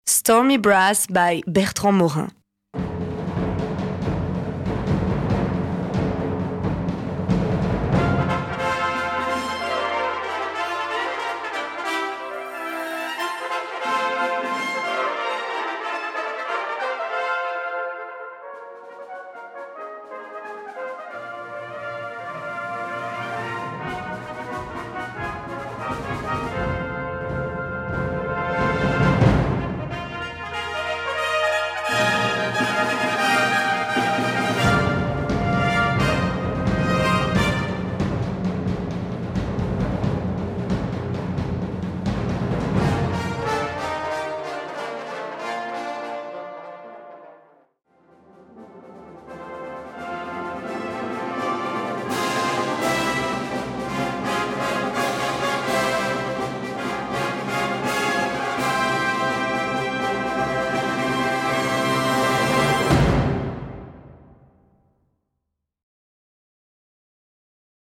Brass Band
Opening Pieces / Indicatifs